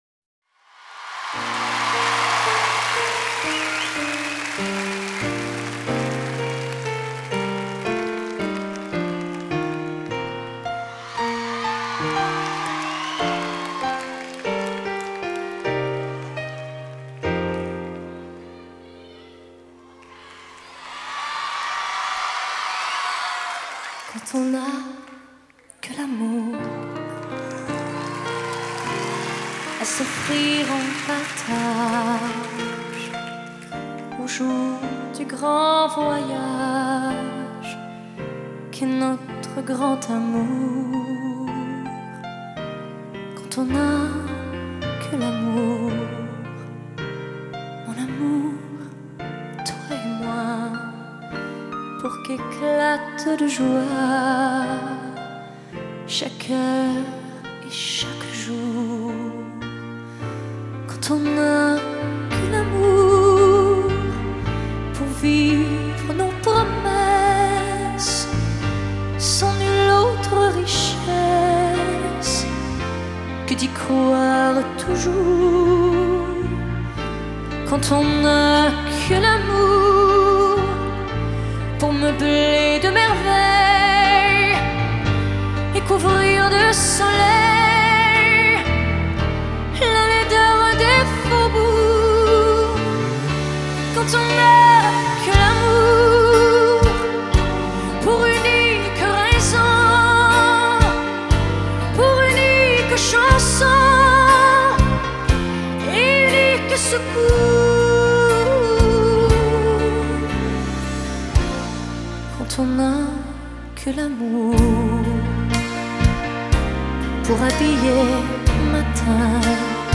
Live à l'Olympia, Paris, France - September 1994